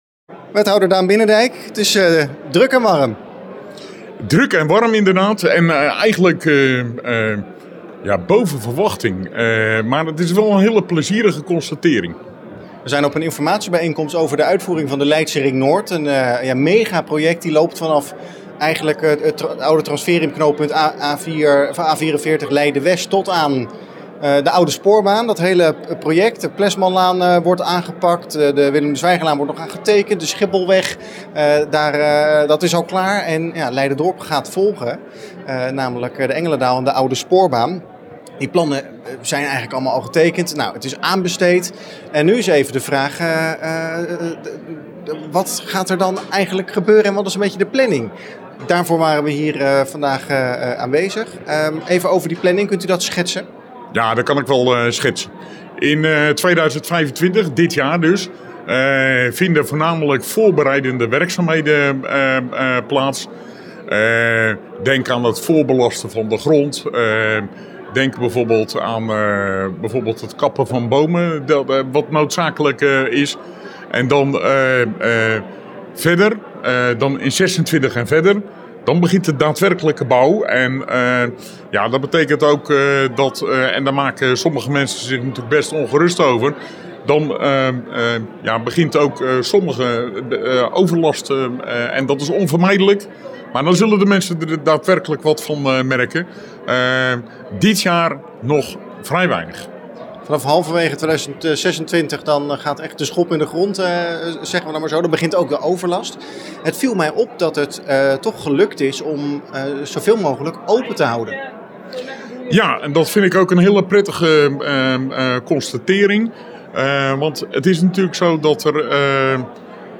Informatieavond over werkzaamheden Leidse Ring Noord trekt volle zaal
In AREA071 op de Baanderij in Leiderdorp kwamen dinsdagavond tussen de 150 en 200 inwoners bijeen voor een informatiebijeenkomst over de geplande werkzaamheden aan de tracédelen Engelendaal en Oude Spoorbaan van de Leidse Ring Noord.
Wethouder Daan Binnendijk over de Leidse Ring Noord.
Wethouder-Daan-Binnendijk-over-de-Leidse-Ring-Noord.mp3